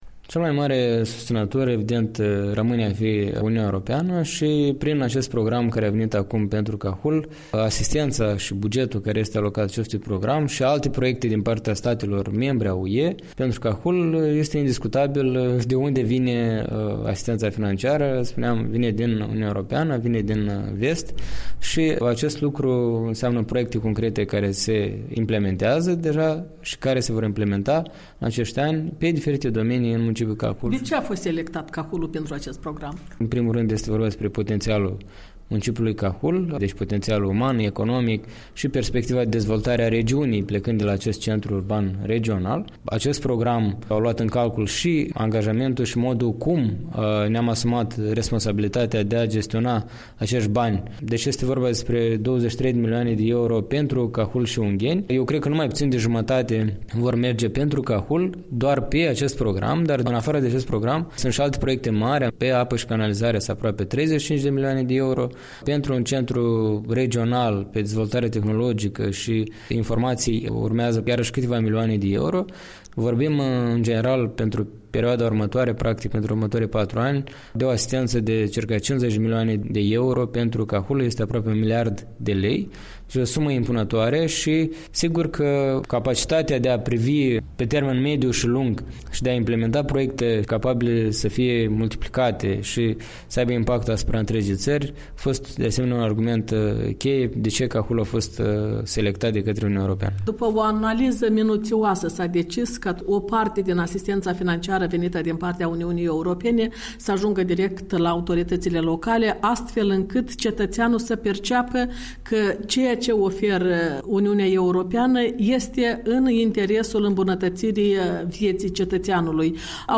Un interviu cu primarul de Cahul, Nicolae Dandiș.